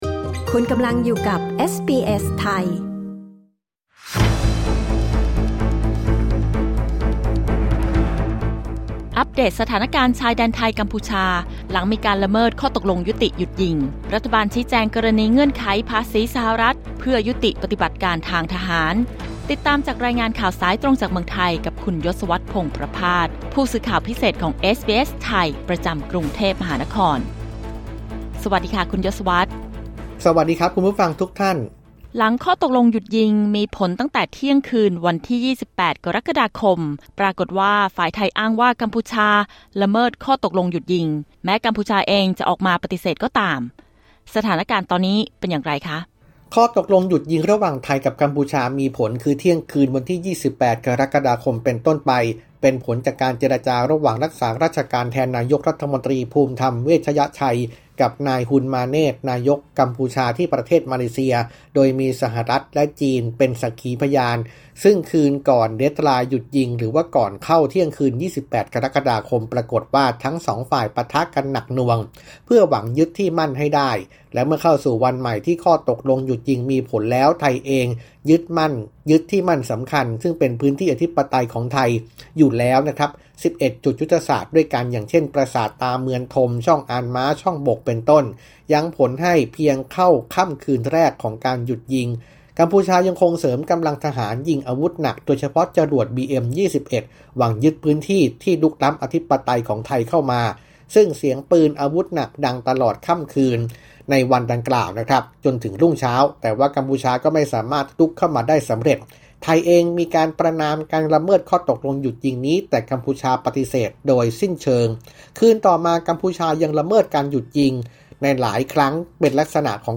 ข่าวไทย 31 กรกฎาคม 2568: สถานการณ์ชายแดนหลังละเมิดข้อตกลงหยุดยิง รัฐฯ แจงกรณีภาษีสหรัฐฯ กับยุติปฎิบัติการทหาร